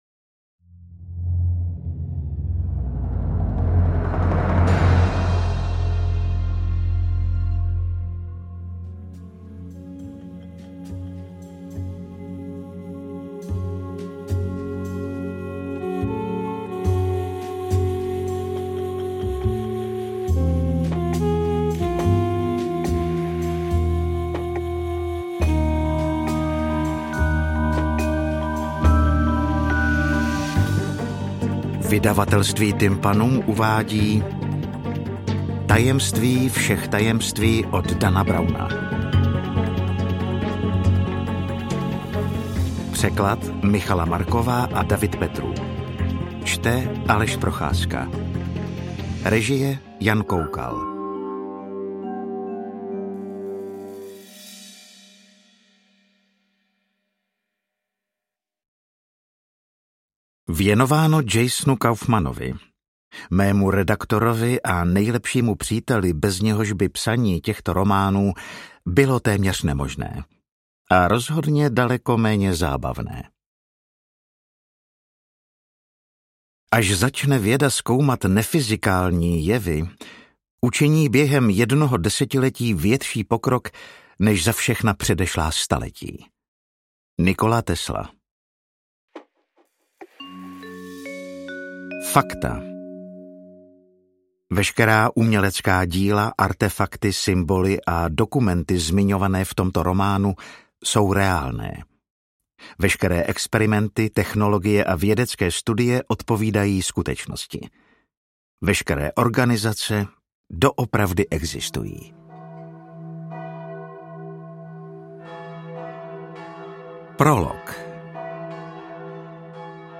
Interpret:  Aleš Procházka
AudioKniha ke stažení, 141 x mp3, délka 27 hod. 50 min., velikost 1532,5 MB, česky